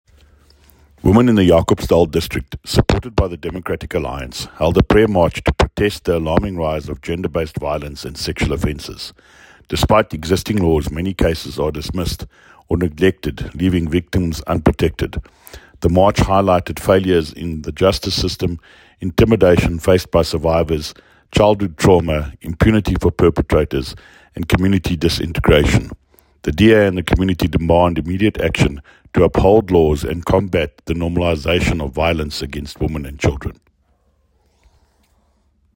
Afrikaans soundbites by David Mc Kay MPL  and Sesotho soundbite by Jafta Mokoena MPL with images here, here, and here